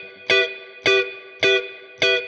DD_StratChop_105-Emin.wav